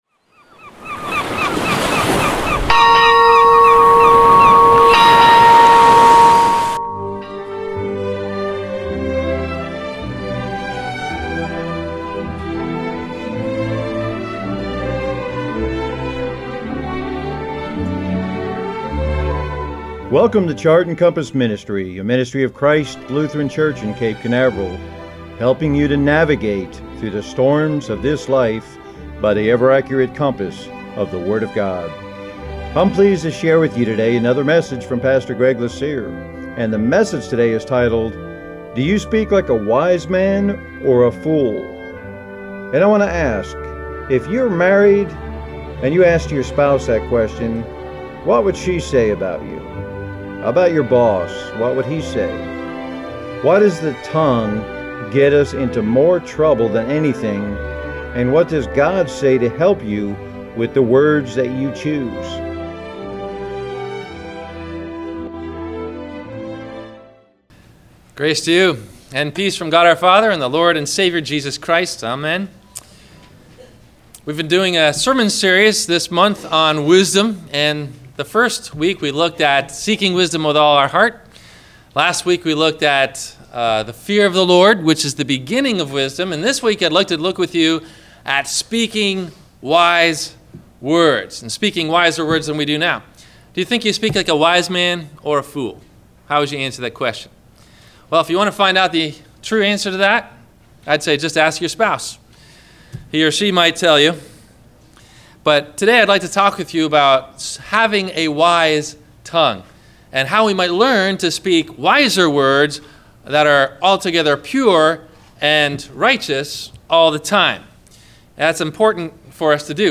Do You Speak Like a Wise Man or a Fool? – WMIE Radio Sermon – May 11 2015